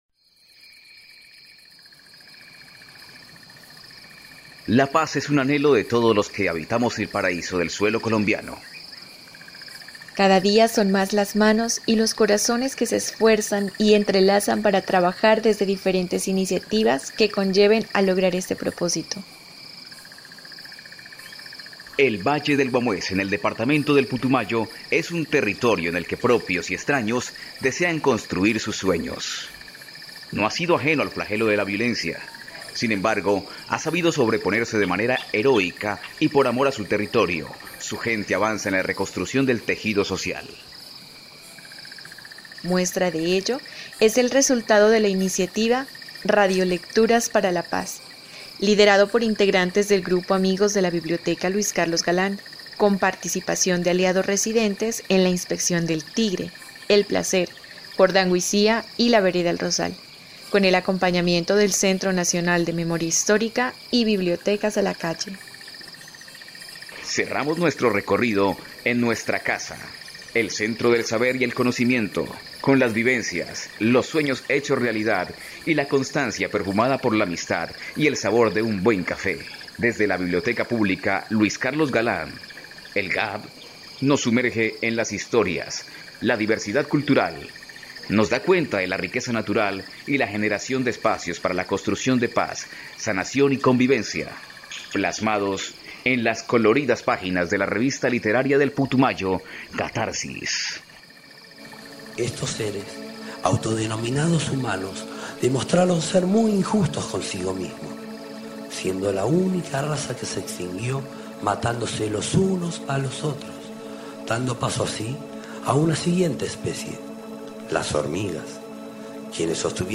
Charla sobre la Biblioteca Pública Luis Carlos Galán.